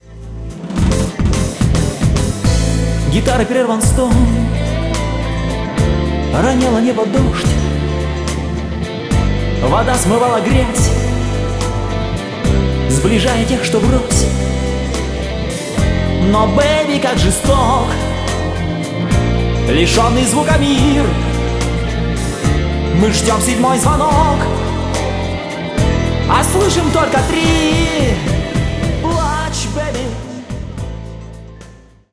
• Жанр: Блюз
Записано в студии г. Екатеринбург.